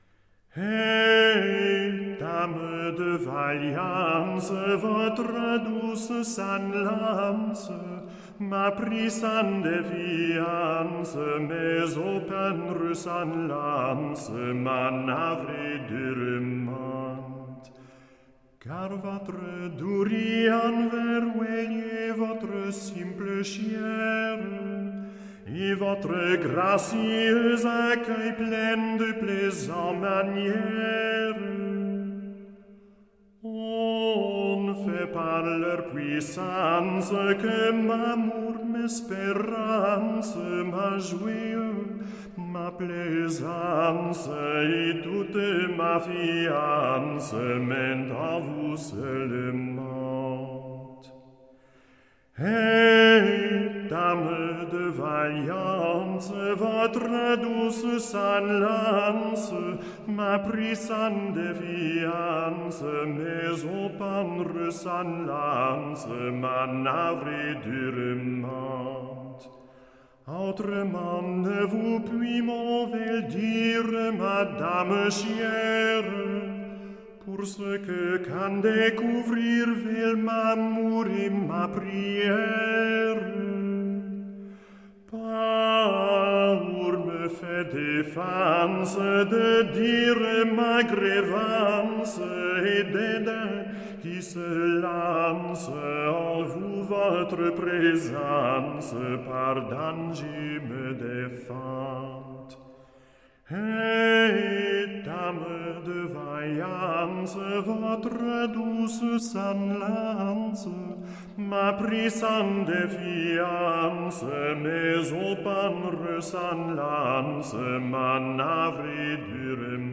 Vocal [89%] Choral [11%]